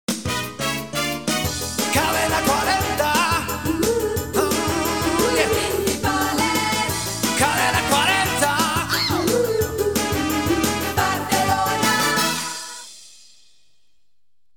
Indicatiu "Soul" de l'emissora
FM